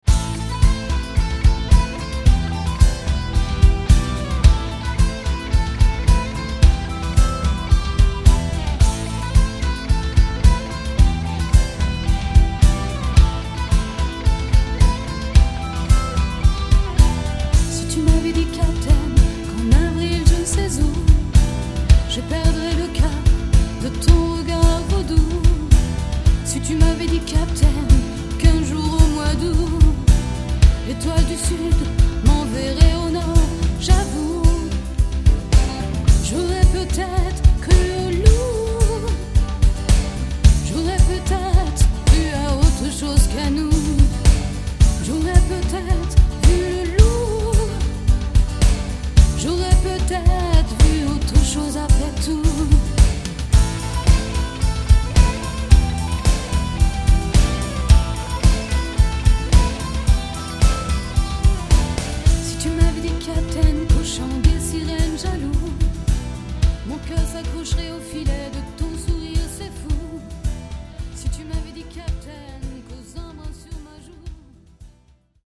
pop music (en ce moment au studio)